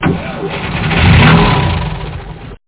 EngineStart.mp3